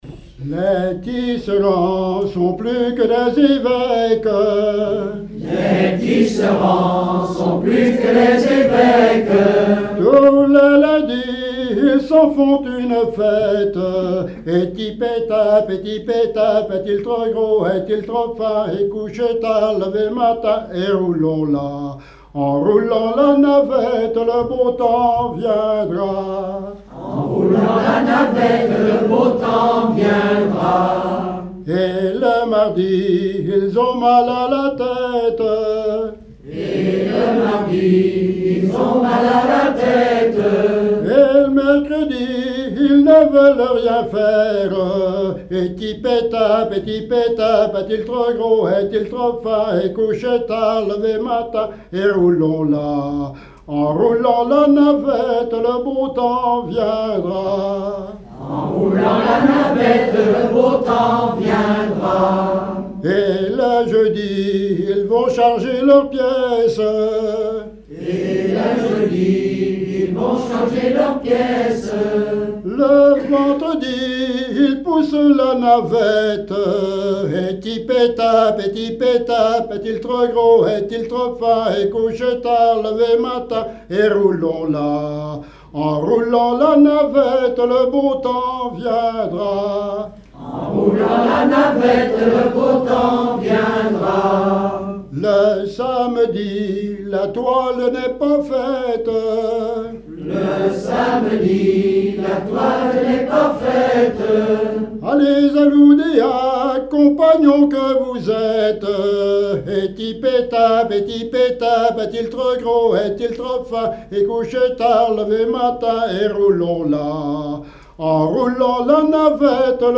Genre énumérative
Veillée
Pièce musicale inédite